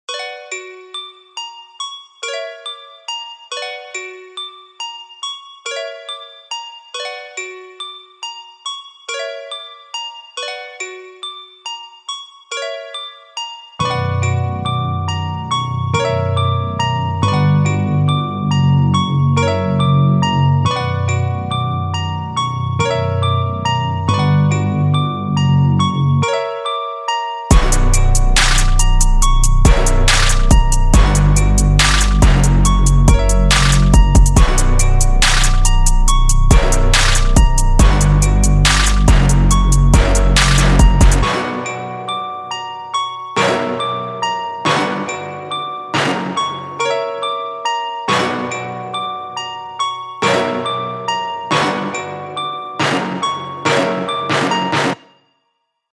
预期重的808音调，脆脆的军鼓和掌声，猛烈的踢鼓，快速的踩hat和旋律会从一开始就一直持续出现在听众的耳朵中。